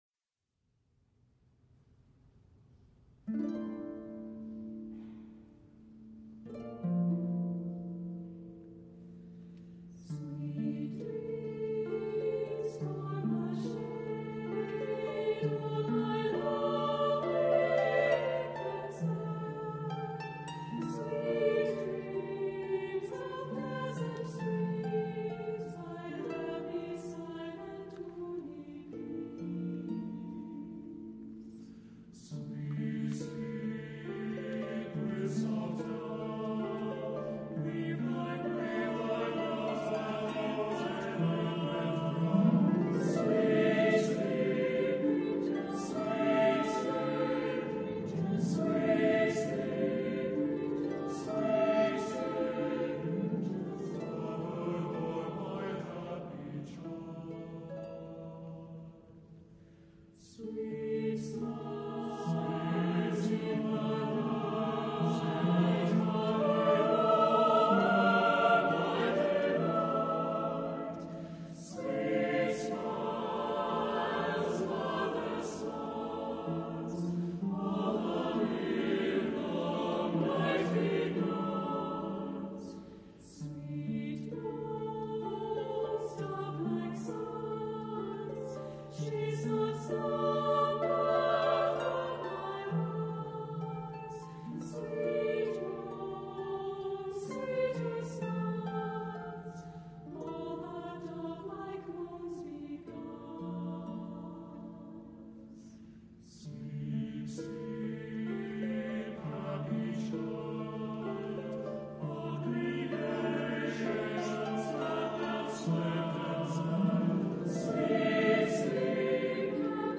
Accompaniment:      With Harp
Music Category:      Choral
with a haunting melody